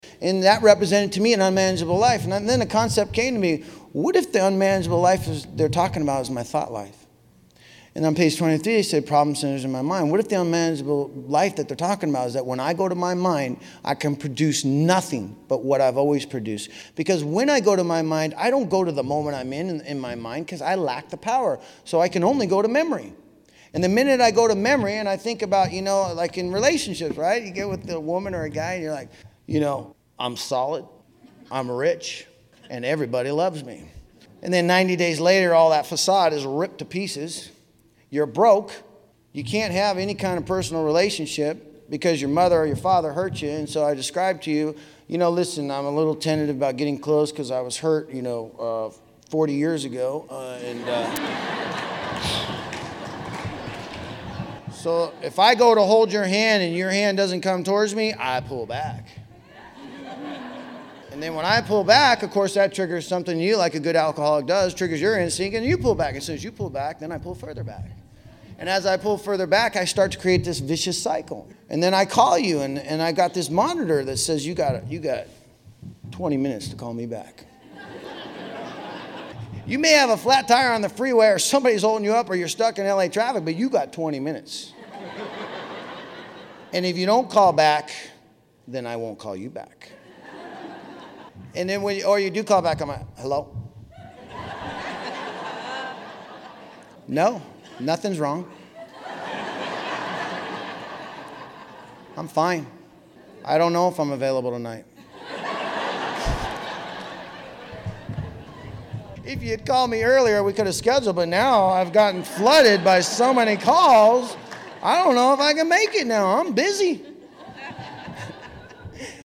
A convention recording sharing personal recovery from addictionism, highlighting the challenges of early sobriety, the return of ego, and the need for both the Recovery program and spiritual connection to maintain lasting change.